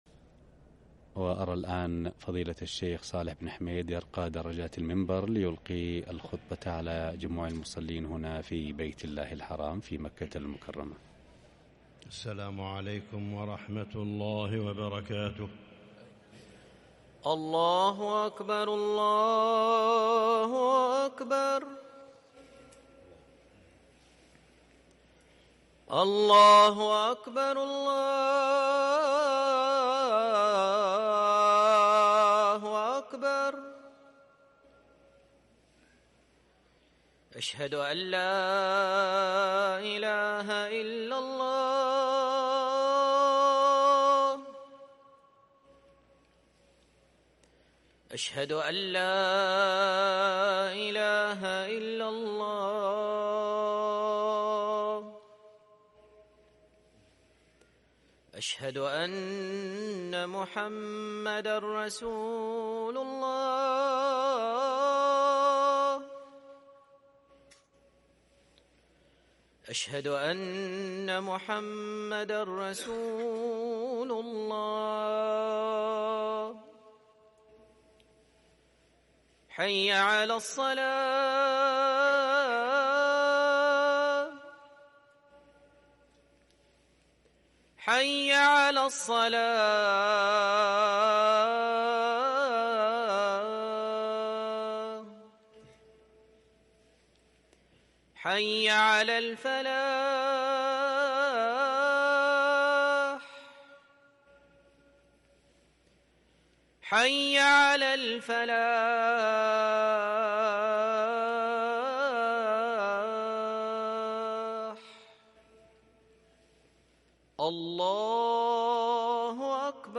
أذان الجمعة الثاني للمؤذن عصام خان الجمعة 27 صفر 1444هـ > ١٤٤٤ 🕋 > ركن الأذان 🕋 > المزيد - تلاوات الحرمين